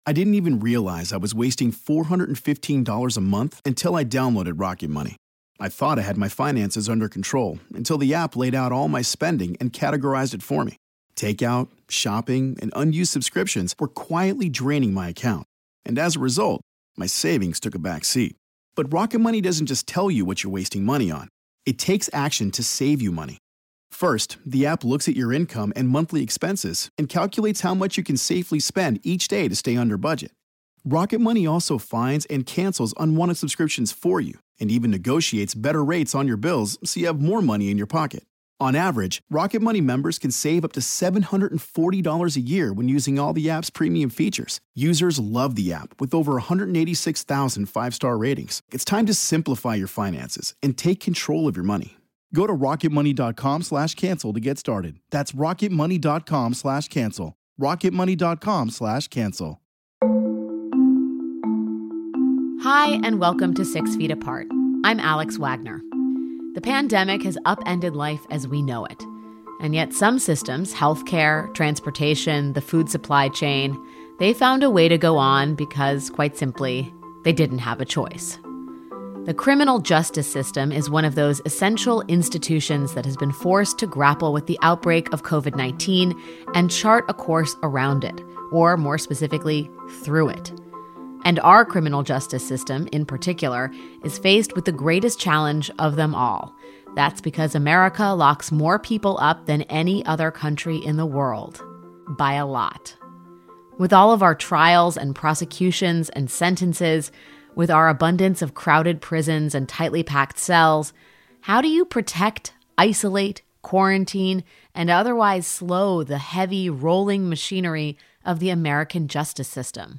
This week’s episode looks at America’s criminal justice system as prosecutors and inmates alike manage unprecedented challenges. First, Alex speaks to San Francisco's new District Attorney, Chesa Boudin.